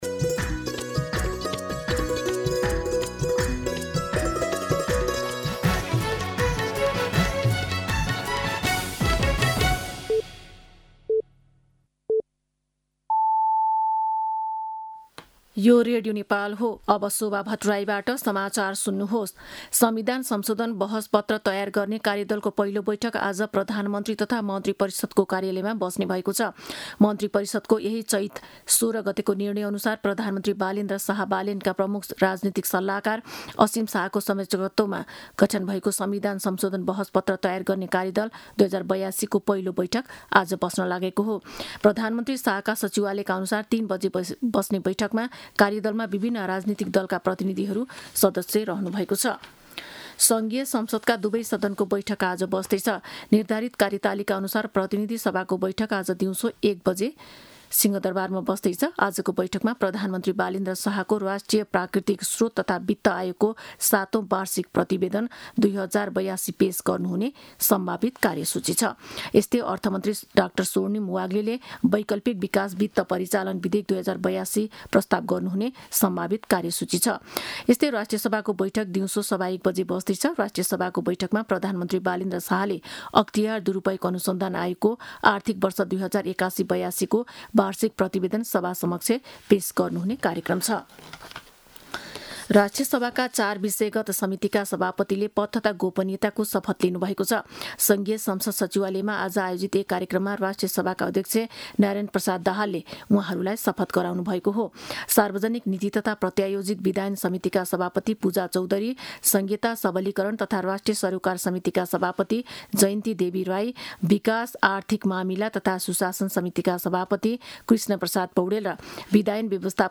दिउँसो १ बजेको नेपाली समाचार : २५ चैत , २०८२
1-pm-News-12-25.mp3